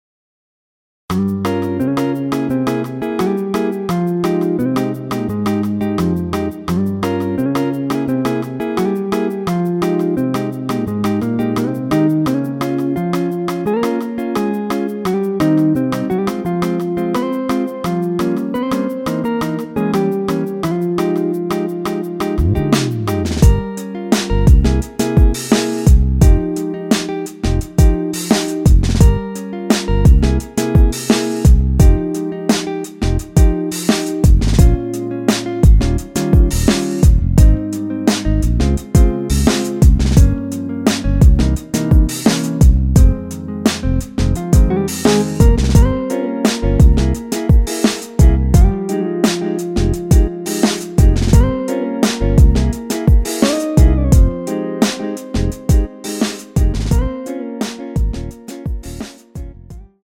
원키에서(-2)내린 MR입니다.
Fm
앞부분30초, 뒷부분30초씩 편집해서 올려 드리고 있습니다.
중간에 음이 끈어지고 다시 나오는 이유는